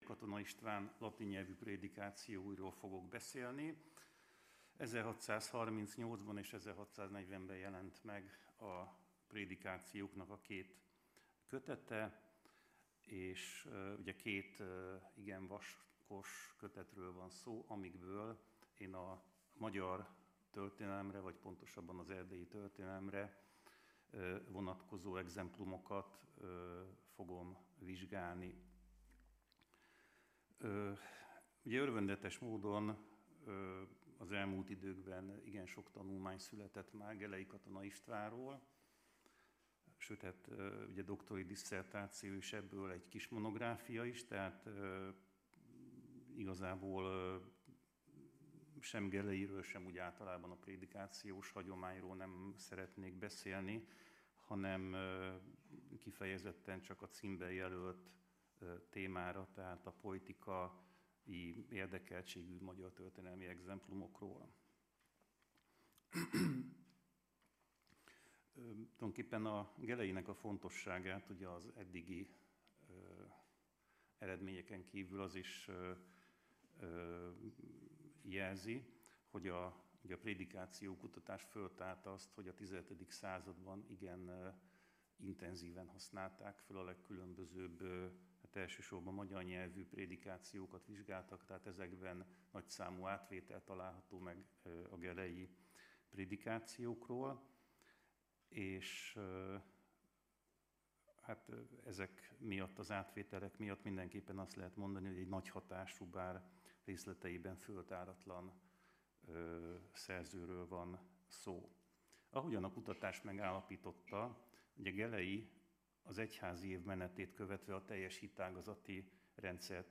Hagyományőrzés és önbecsülés. Száz éve született Tarnai Andor , Ötödik ülés